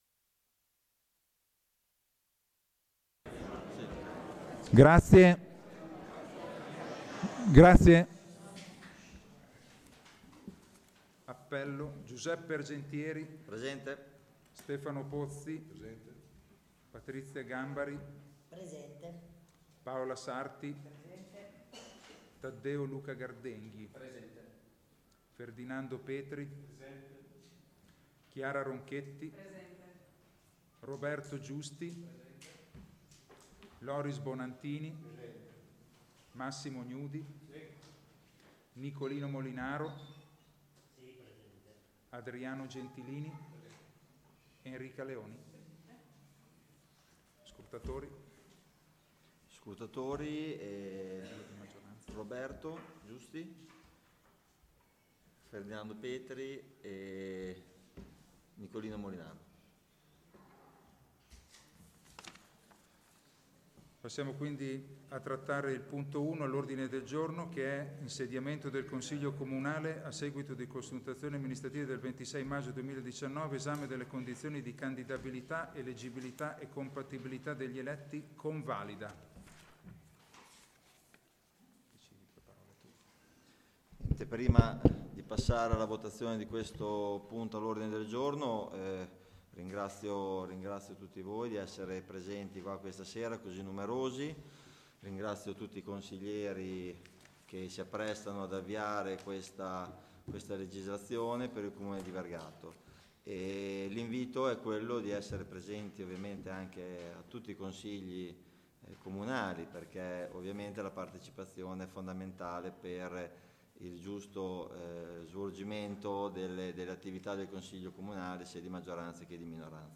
Consiglio Comunale del 23 gennaio 2019